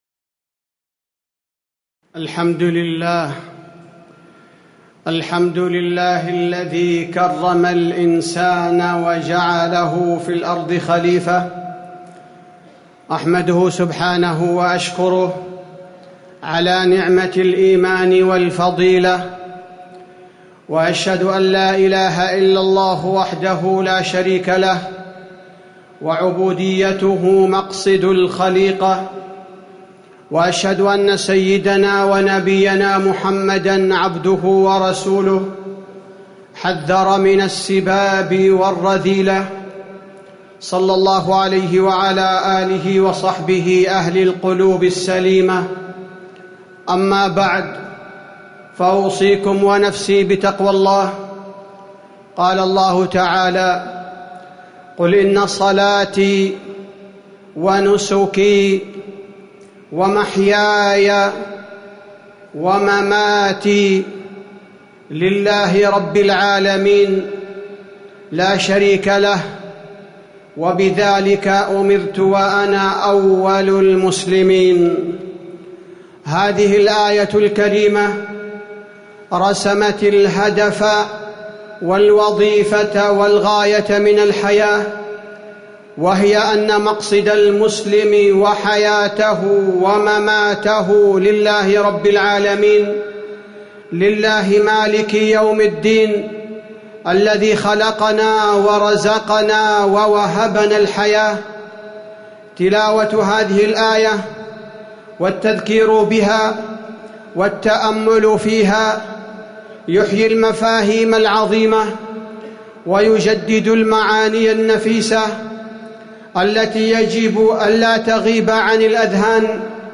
تاريخ النشر ١٨ محرم ١٤٤٠ هـ المكان: المسجد النبوي الشيخ: فضيلة الشيخ عبدالباري الثبيتي فضيلة الشيخ عبدالباري الثبيتي حياة المسلم ومماته